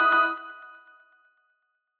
フリー効果音：システム44
システムSE系効果音、第44弾！キラっとした効果音です！会話やゲームにそっと挟むのにぴったり！